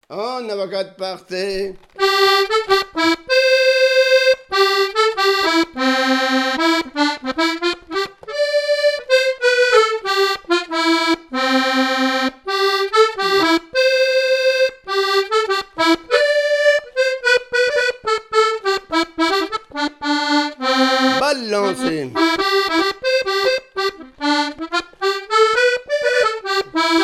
Mémoires et Patrimoines vivants - RaddO est une base de données d'archives iconographiques et sonores.
danse : quadrille : avant-quatre
Répertoire à l'accordéon diatonique
Pièce musicale inédite